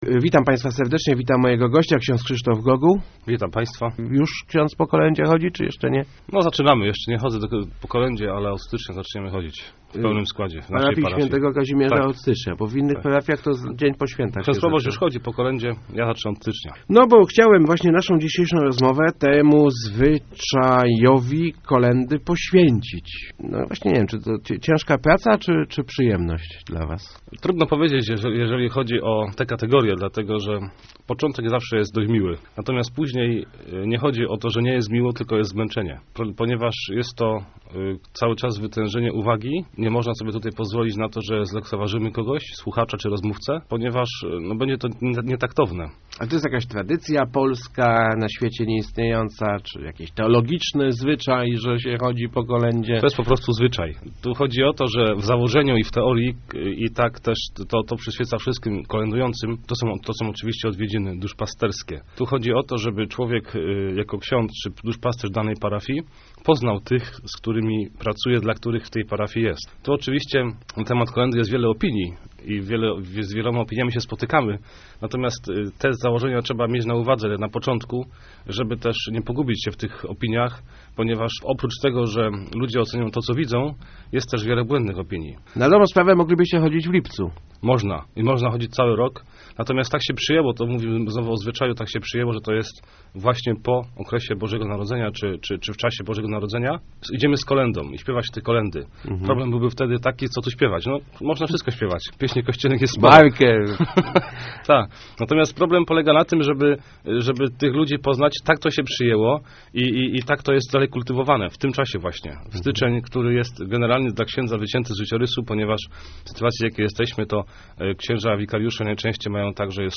Rozmowach Elki